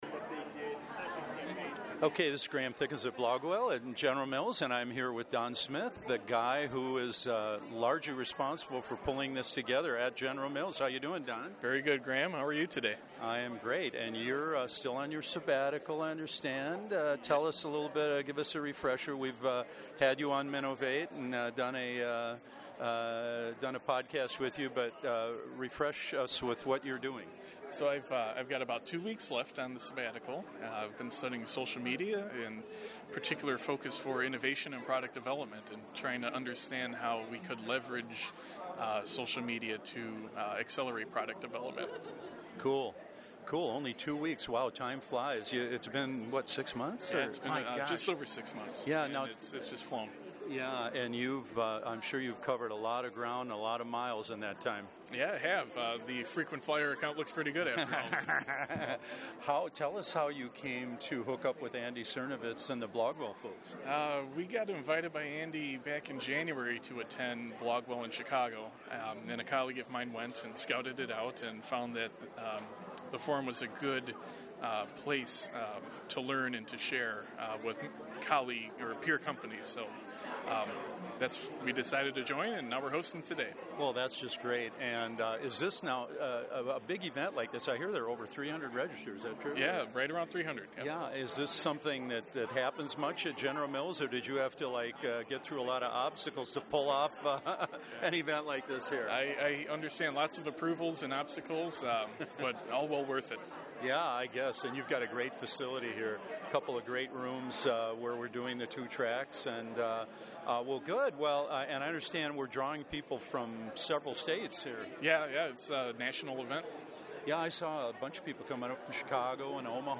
My Interviews: I took along my trusty studio-grade handheld recorder (the Olympus LS-10) and grabbed seven brief audio interviews before and after the sessions, and during breaks.